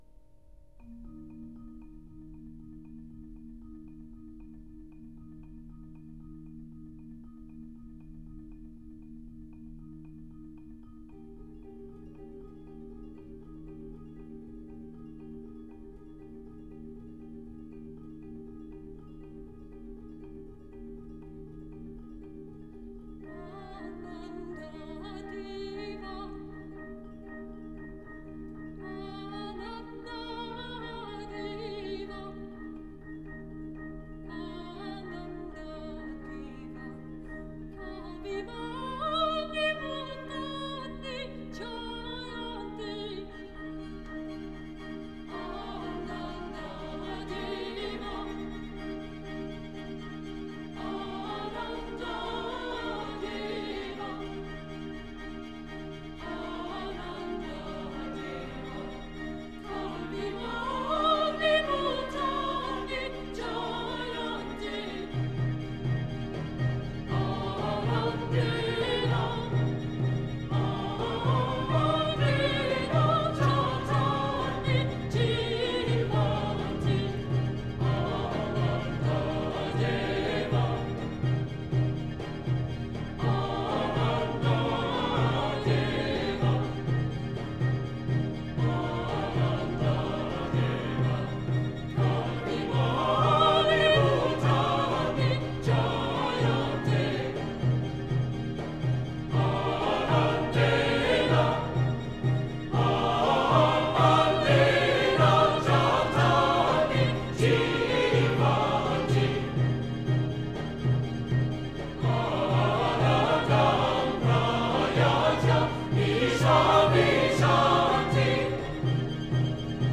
soprano
Recorded in 1992 at the Centennial Concert Hall in Winnipeg